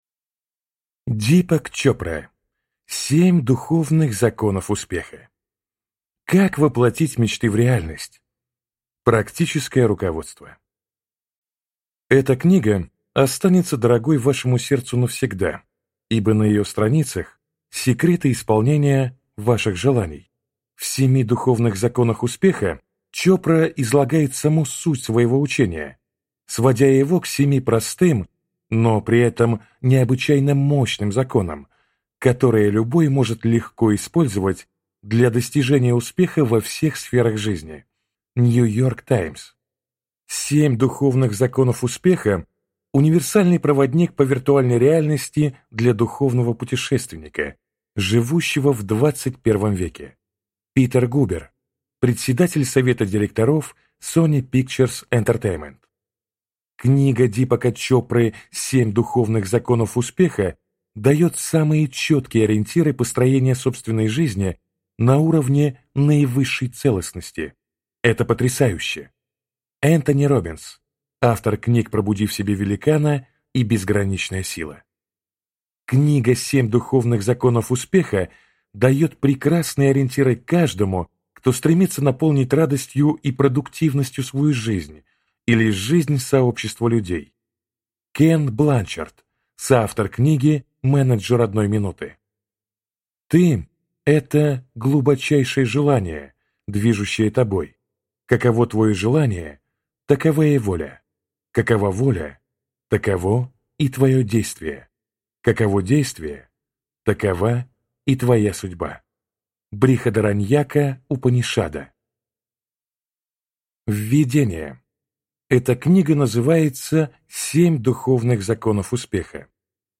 Аудиокнига Семь духовных законов успеха | Библиотека аудиокниг